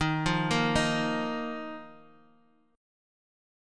D#sus2 chord